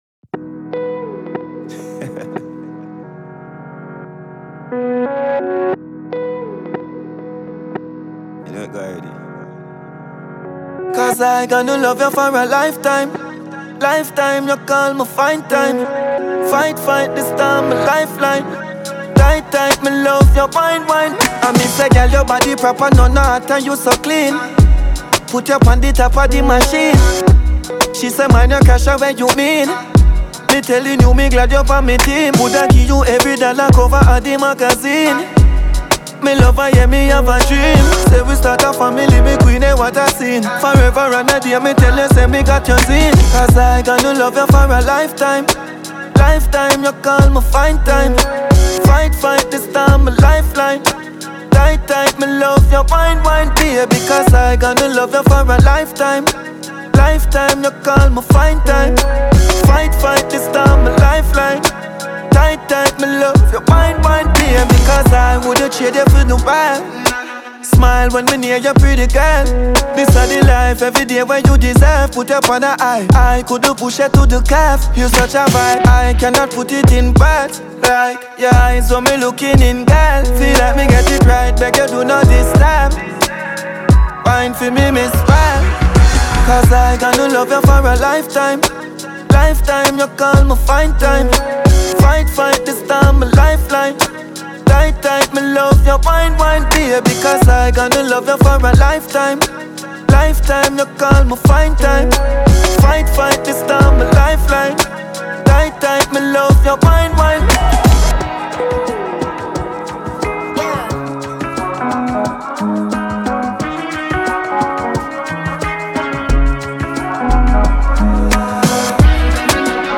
Latest Dancehall Song
a powerful and emotional Jamaican dancehall record
Genre: Dancehall / Conscious